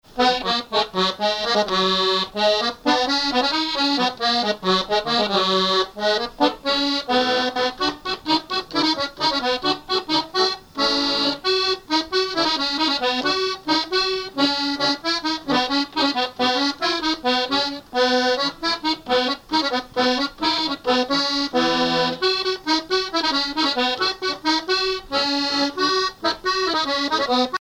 branle : courante, maraîchine
musique à danser à l'accordéon diatonique
Pièce musicale inédite